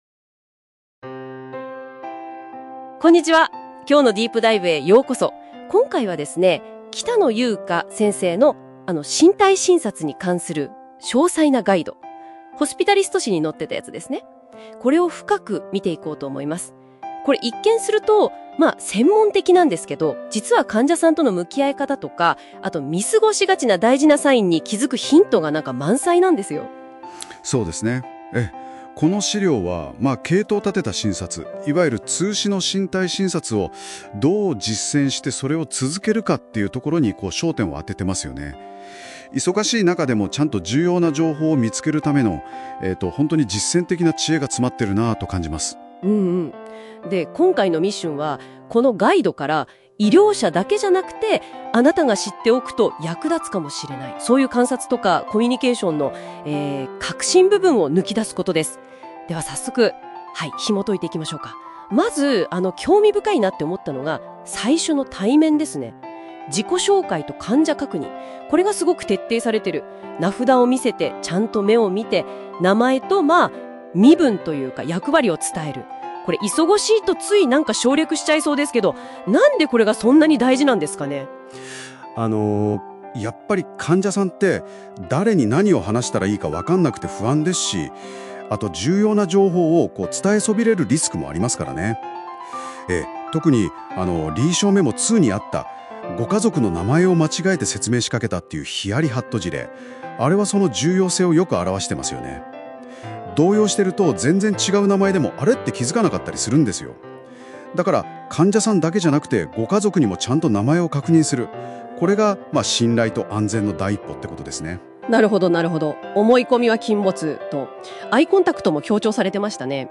※AI音声の漢字の読み間違いが多い点はご了承ください